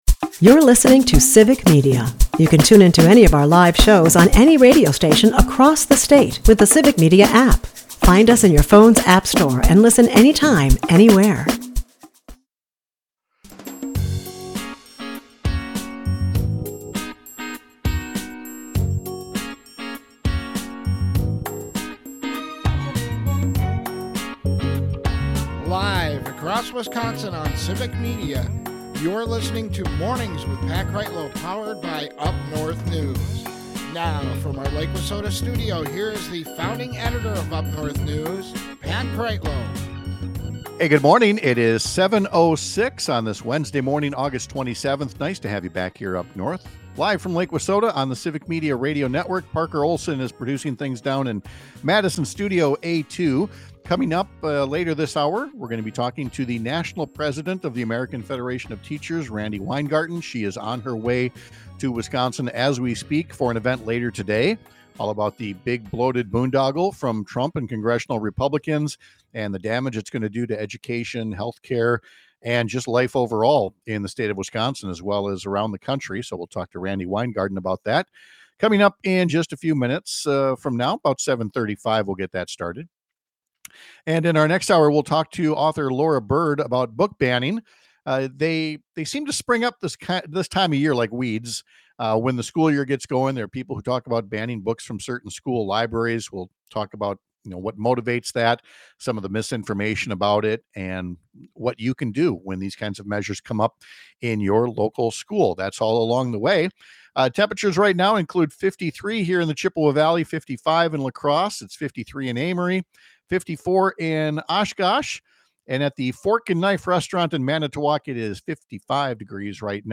It would be enough for leaders from education, healthcare, labor, and local services to call attention to the damage being done by the Trump administration separately. Today in Eau Claire, they’ll do it together and we’ll talk to the national president of the American Federation of Teachers, Randi Weingarten, about the ongoing work to educate the public about the ways children will be impacted by this year’s actions for many years to come.